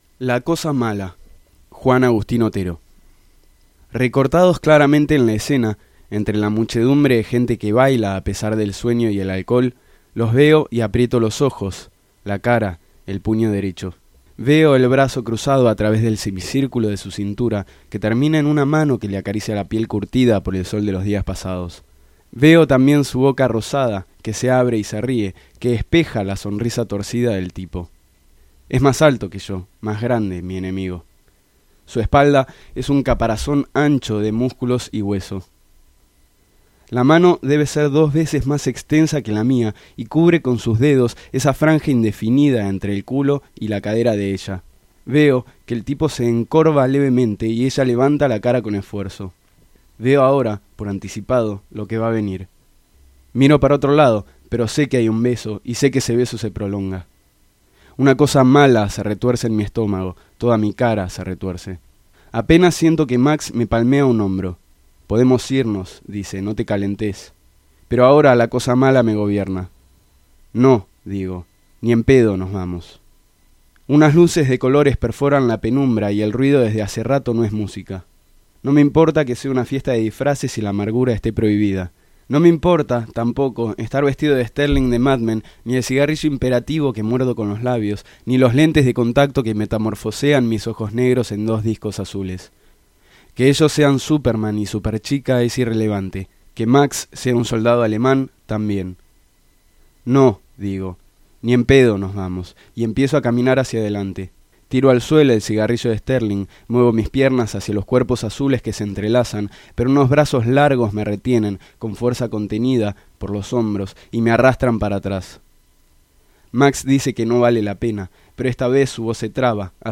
La cosa mala Juan Agustin Otero | Audiocuento